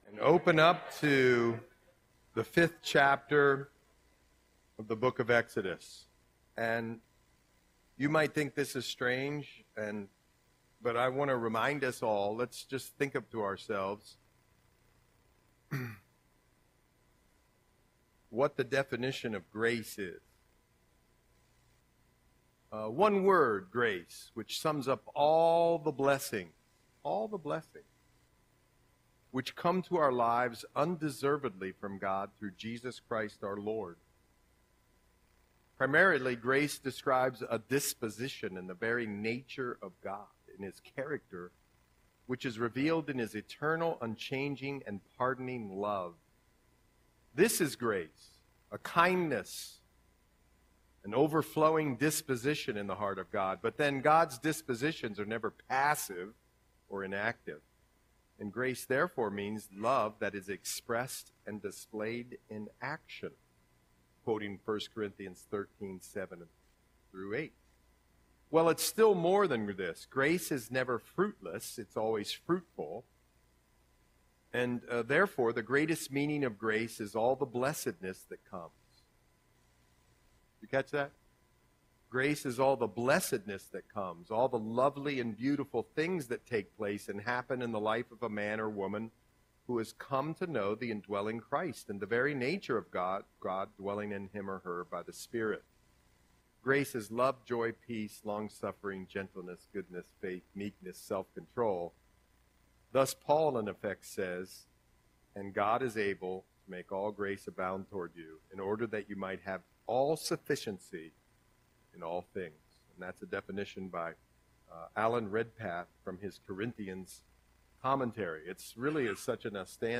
Audio Sermon - November 20, 2024